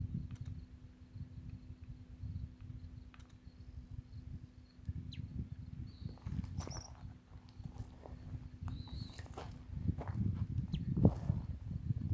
Scientific name: Leistes loyca loyca
English Name: Long-tailed Meadowlark
Detailed location: Dique Paso de las Piedras
Condition: Wild
Certainty: Photographed, Recorded vocal